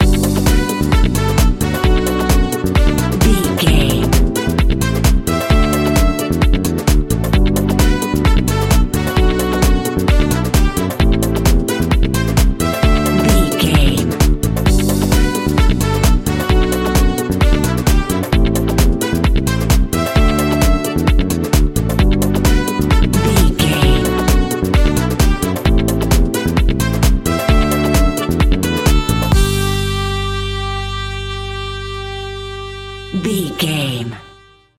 Ionian/Major
groovy
uplifting
energetic
bass guitar
brass
saxophone
drums
electric piano
electric guitar
synth
upbeat
instrumentals